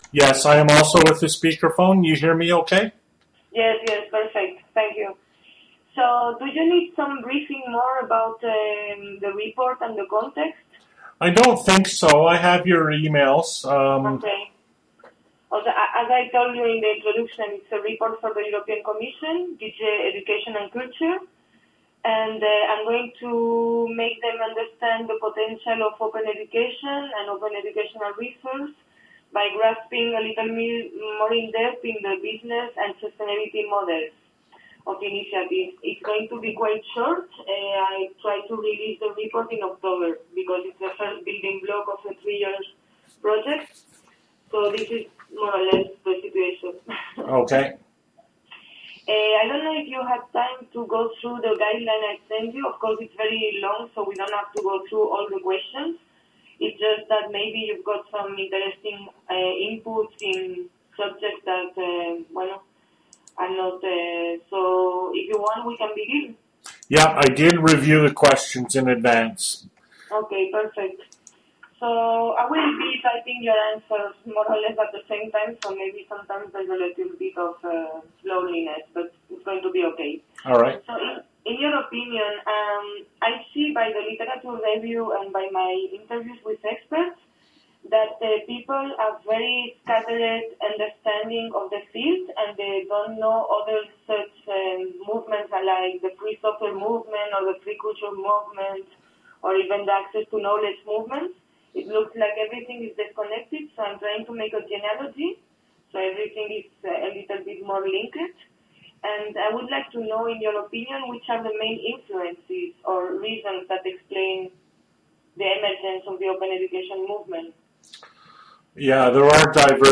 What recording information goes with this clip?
Sorry about the microphone gain, which is a little bit too high. telephone interview